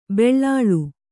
♪ beḷḷāḷu